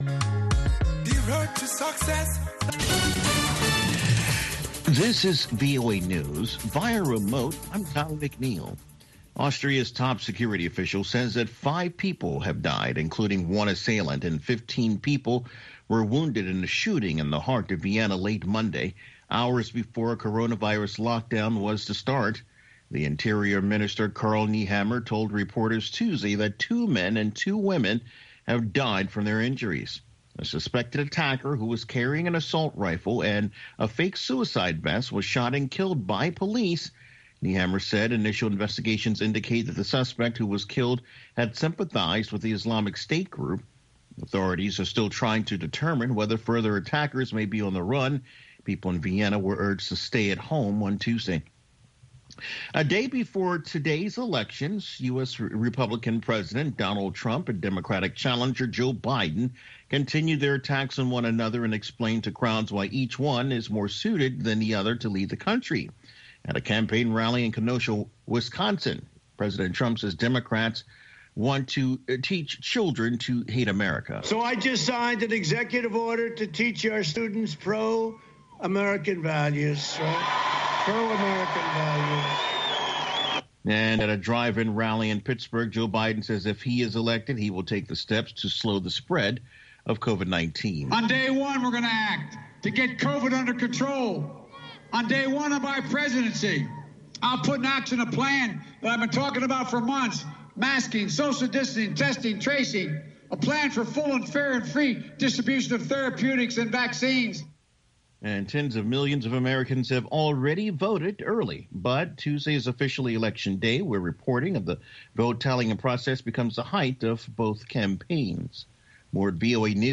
contemporary African music